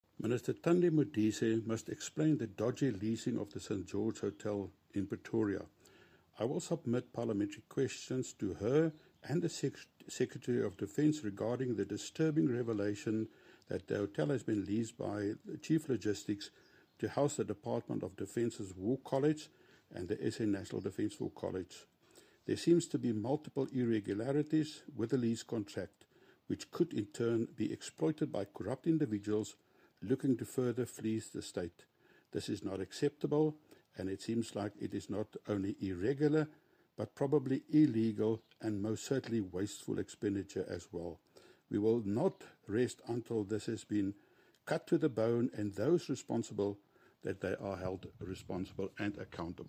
Afrikaans soundbites by Kobus Marais MP.